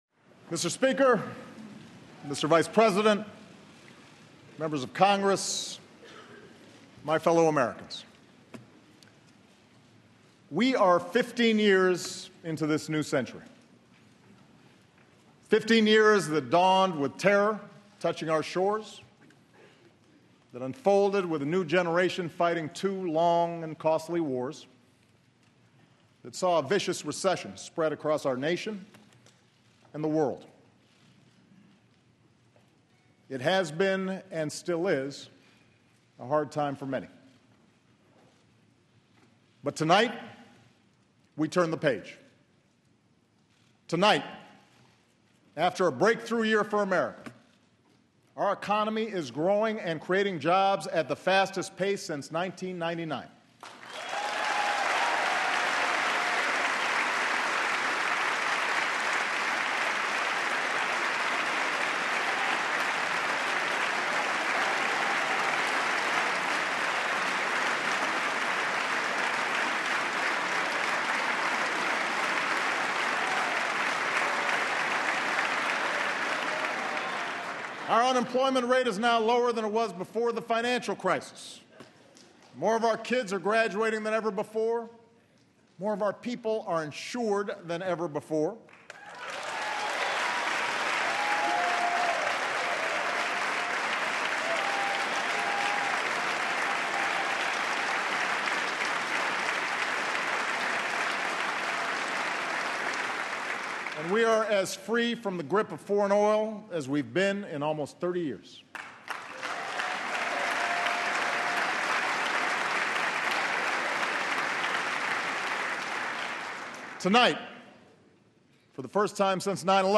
Transcript: President Obama's State Of The Union Address
President Obama's State of the Union address as prepared for delivery on Jan. 20, 2015.